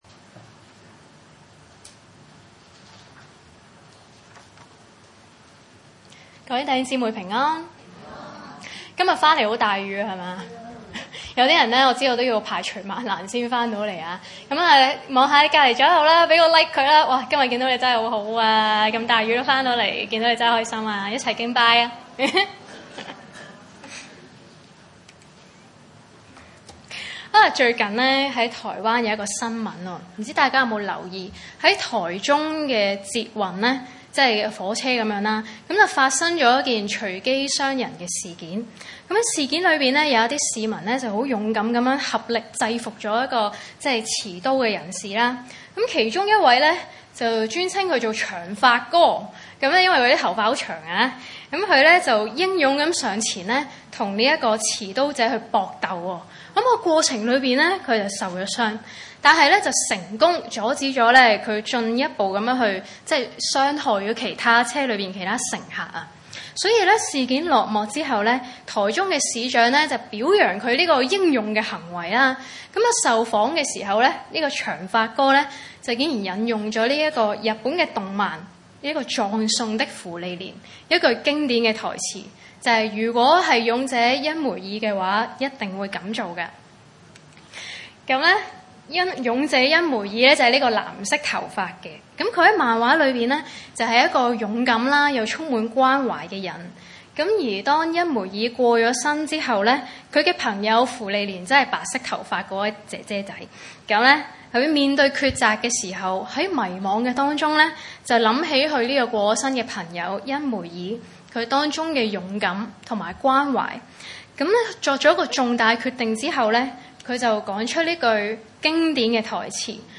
經文: 馬可福音3:20-27 崇拜類別: 主日午堂崇拜 20.